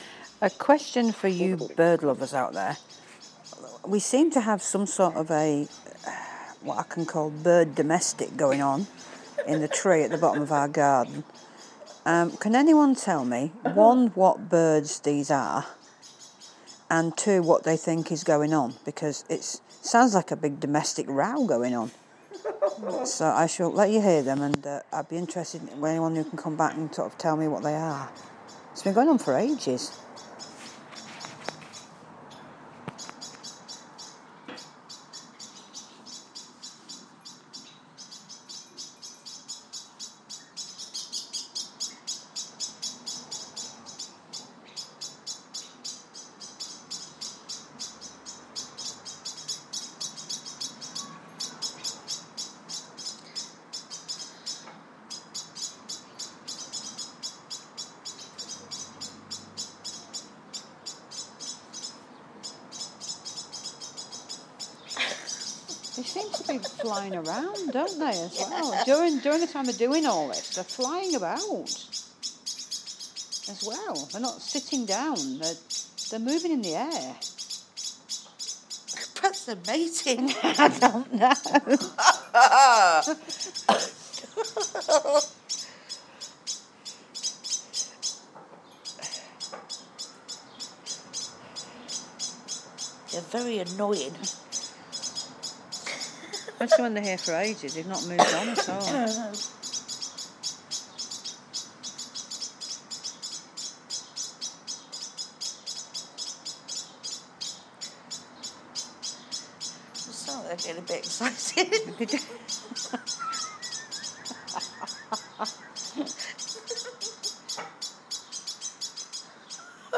Please identify the bird